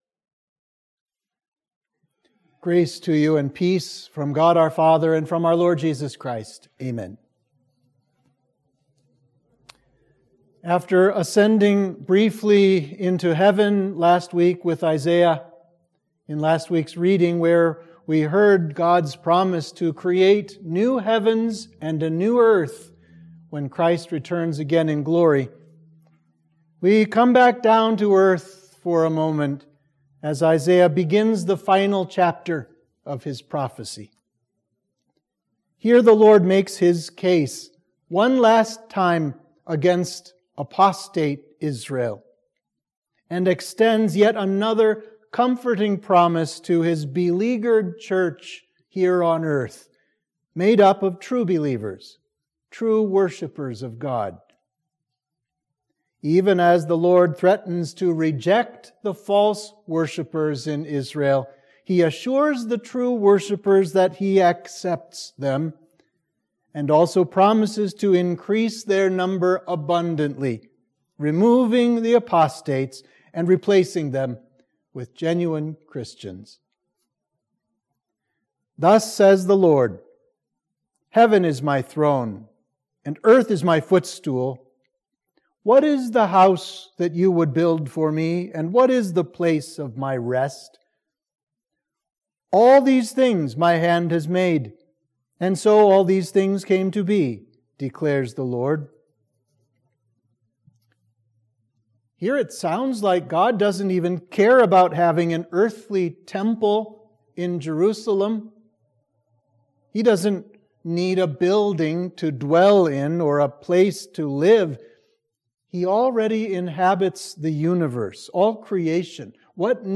Sermon for Midweek of Advent 2